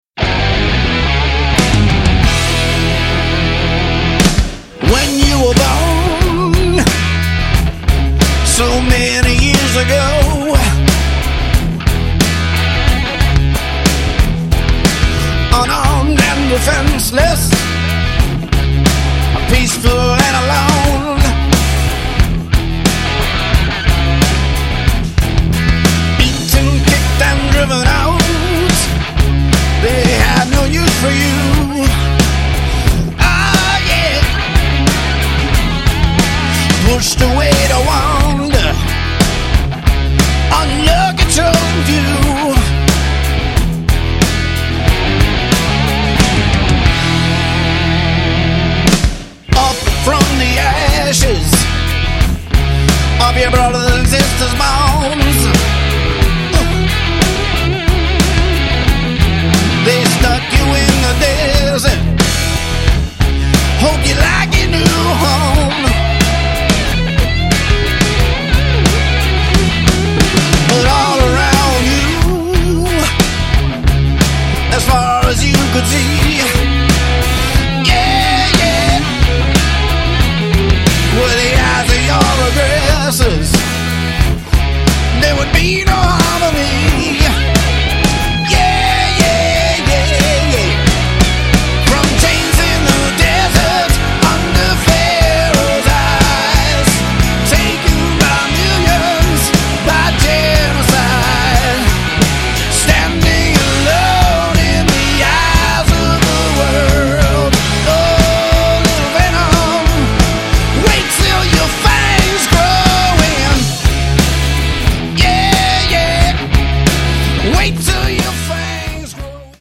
(Hard Rock)
Loaded with earthy, organic ‘70s influenced hard rock